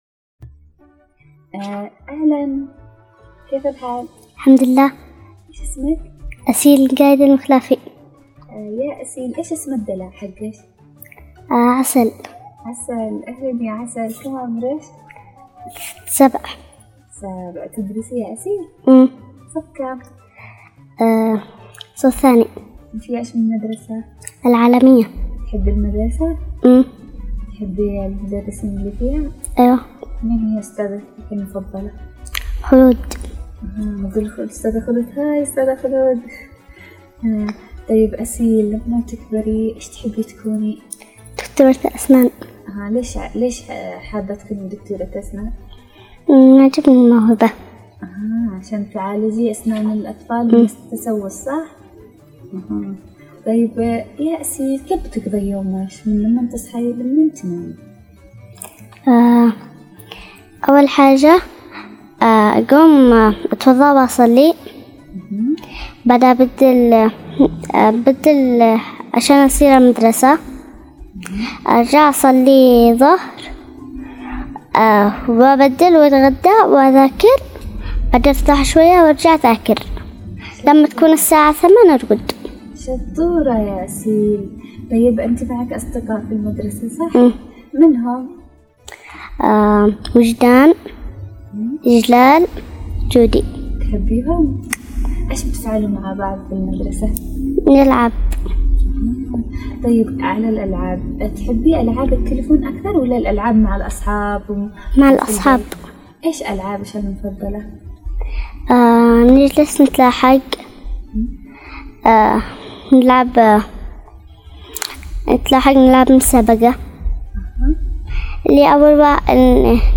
الطفولة هي عالم فيه الكثير من الابداع والمرح والتسلية فتعالوا معنا لنستمتع بكل ما يقوله ويقدمه الأطفال...